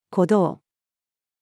鼓動-female.mp3